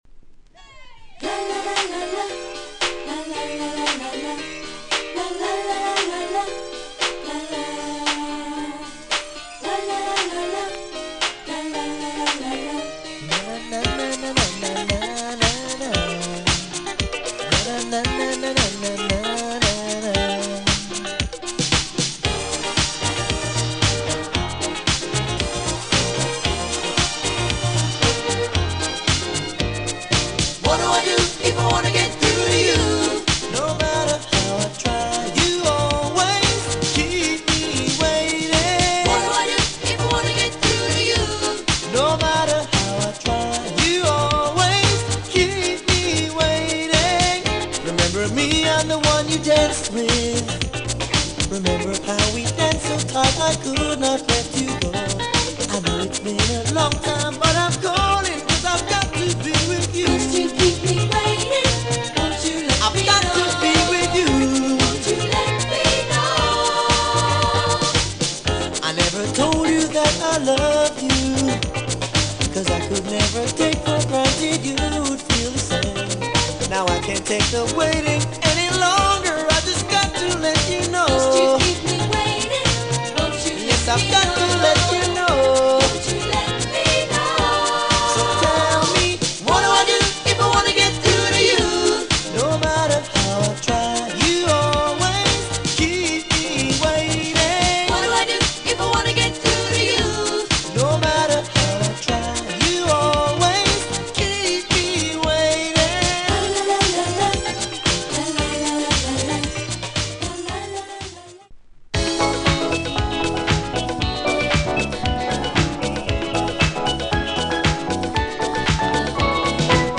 Tropical Disco Soul ほか 試聴
80's特有のサウンドに甘美なヴォーカルがマッチした洒落た感覚はライト・メロウ好きも確実にマスト。